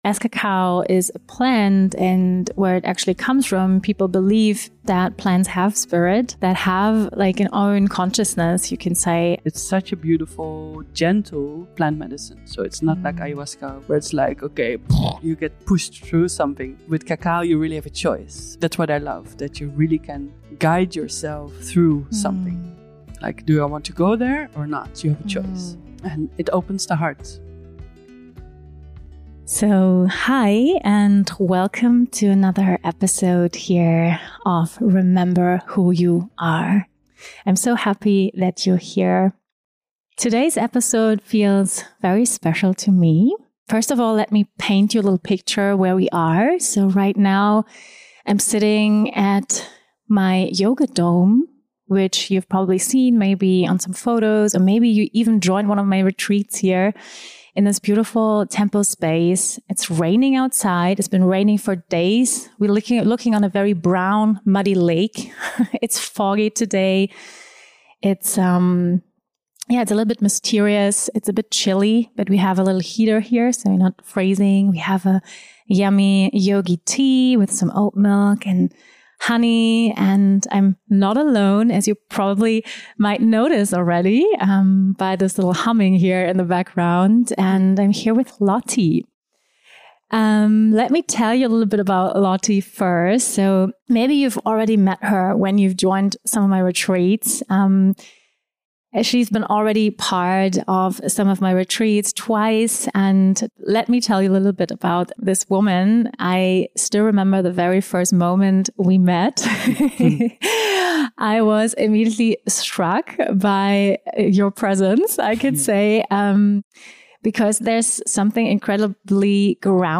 In this conversation, we explore the power of yin — and why our modern world so desperately needs it. This is a warm, heart-opening, cozy dialogue about softness, ritual, and the gentle medicine of cacao.